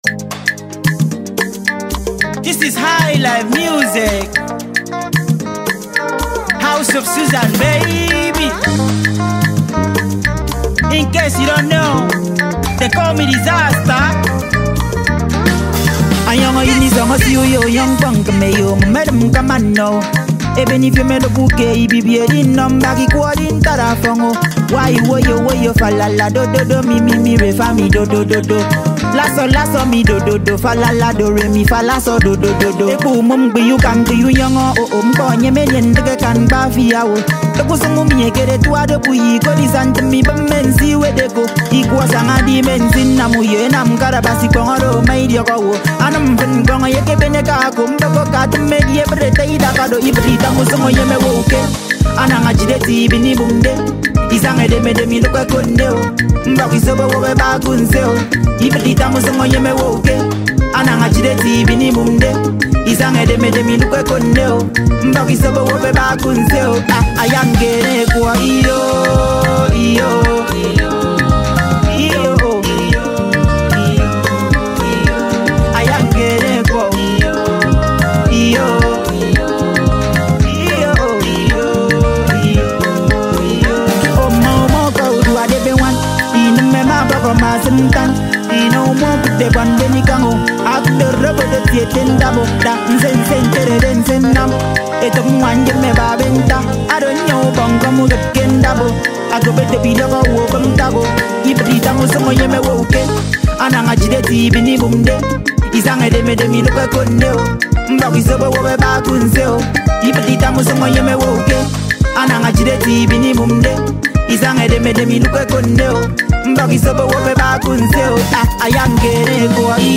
Ibibio rap genre